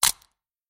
На этой странице собраны звуки перелома костей – от резких щелчков до глухих тресков.
Звук хруста и треск костей при переломе